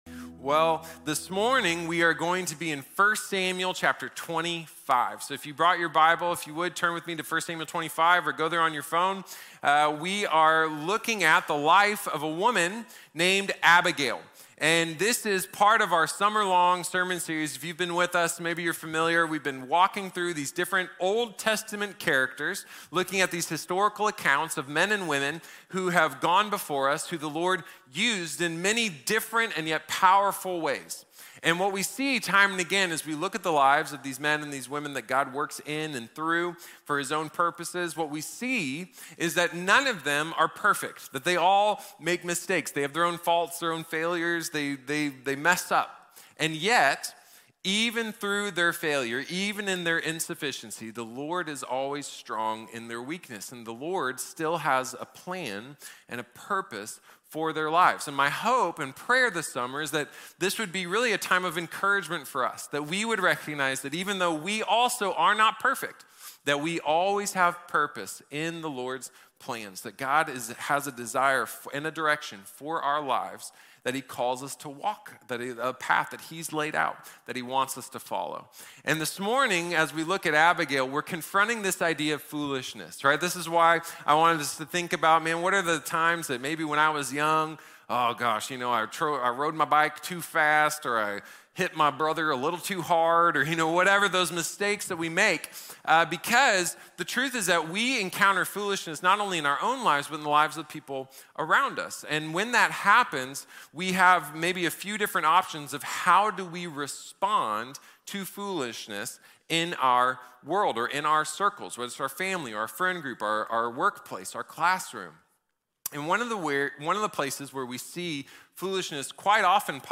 Abigail | Sermón | Iglesia Bíblica de la Gracia